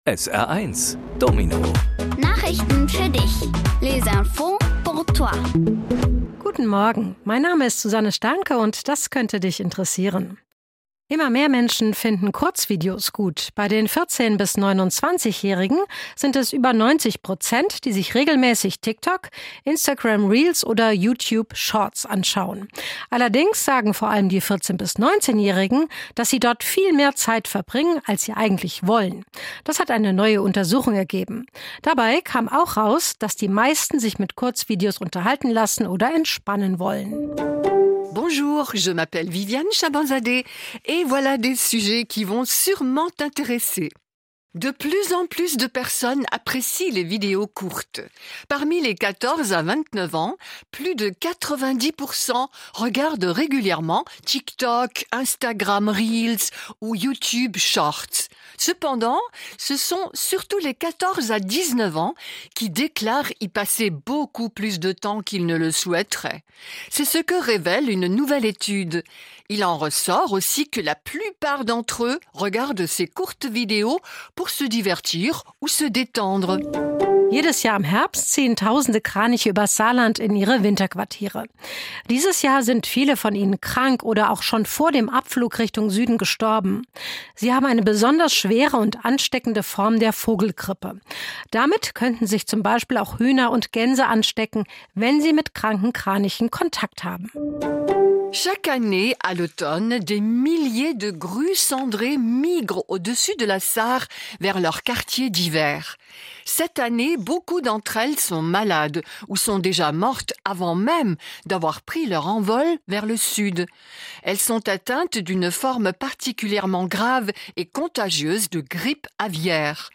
Die wichtigsten Nachrichten der Woche kindgerecht aufbereitet auf Deutsch und Französisch